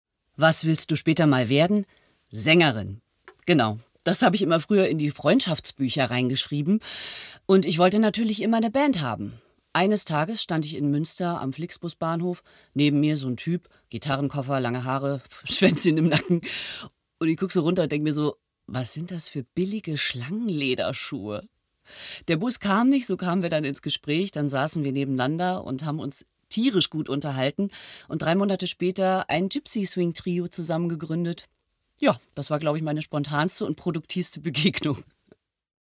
natürliche Stimme